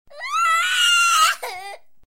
cry2.wav